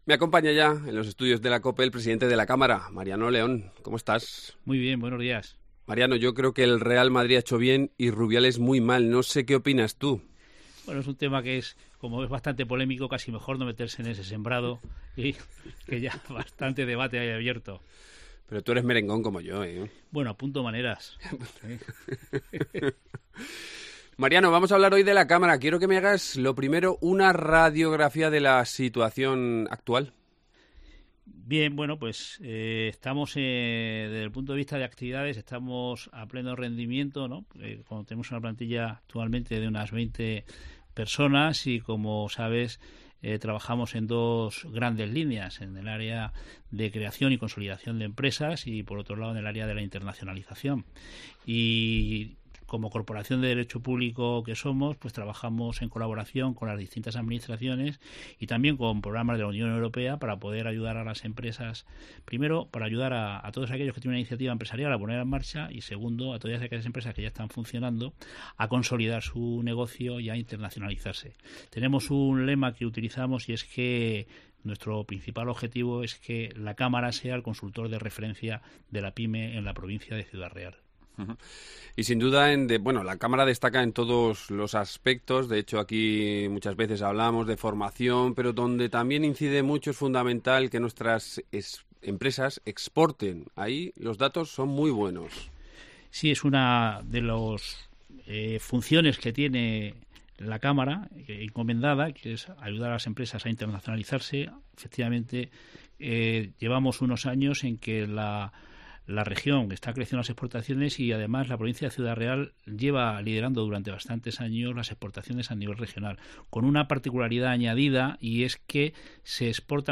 Hoy nos acompaña en los estudios de Cope